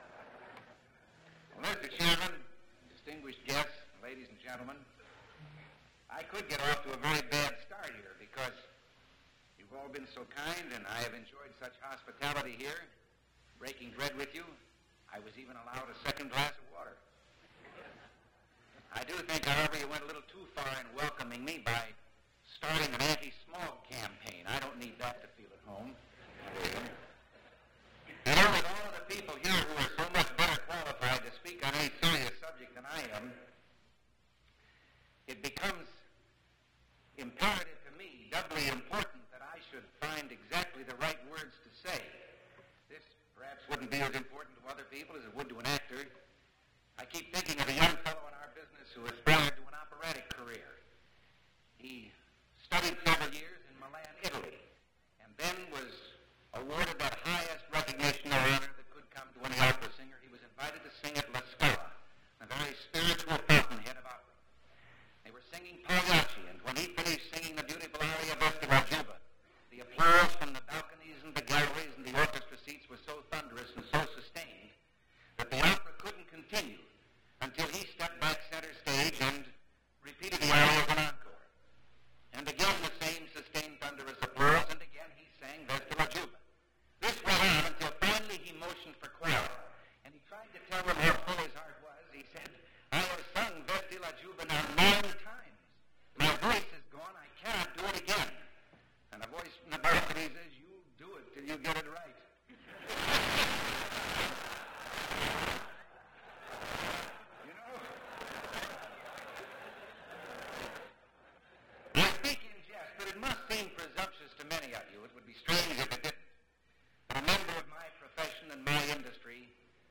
Ronald Reagan's speech to Phoenix Chamber of Commerce Annual Meeting
Reel to Reel Audio Format MP3 Audio file